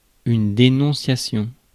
Ääntäminen
Synonyymit mouchardage délation Ääntäminen France: IPA: [de.nɔ̃.sja.sjɔ̃] Haettu sana löytyi näillä lähdekielillä: ranska Käännös Substantiivit 1. denunco Suku: f .